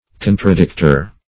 Contradicter \Con`tra*dict"er\